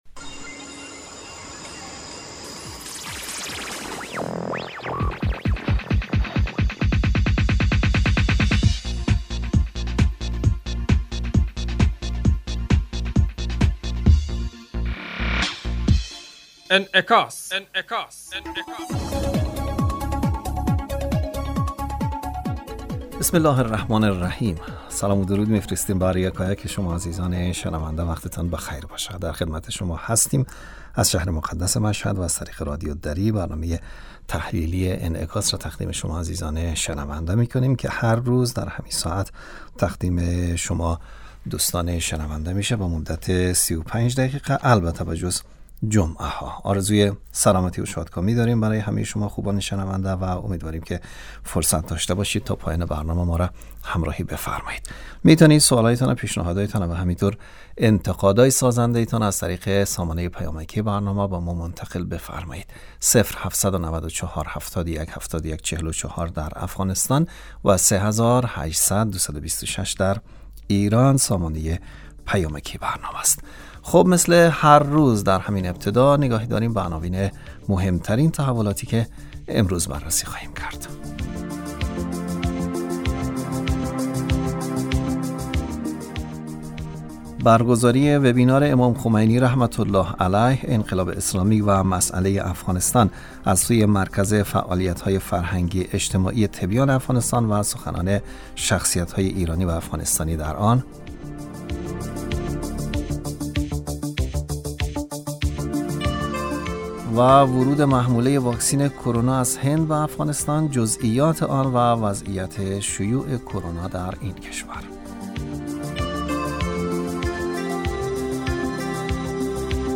ورود محموله واکسین کرونا از هند به افغانستان، جزئیات آن و وضعیت شیوع کرونا در این کشور. برنامه انعکاس به مدت 35 دقیقه هر روز در ساعت 12:15 ظهر (به وقت افغانستان) بصورت زنده پخش می شود.